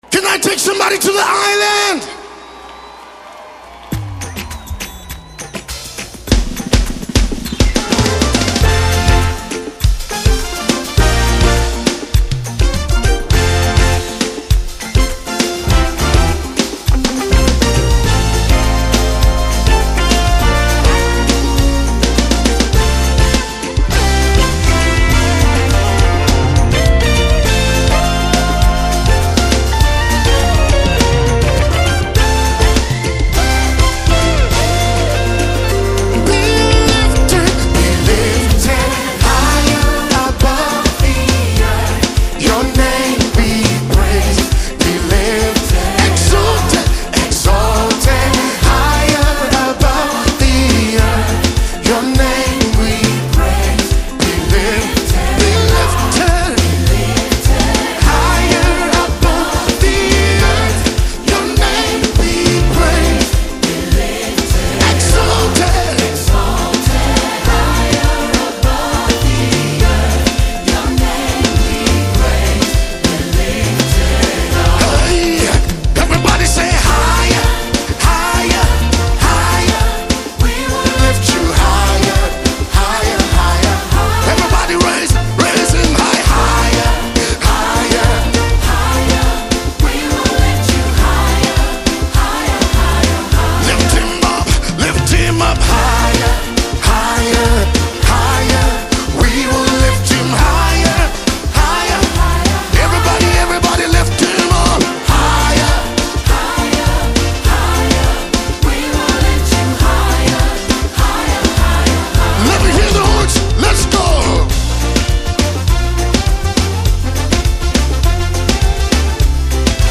a gifted gospel singer and songwriter.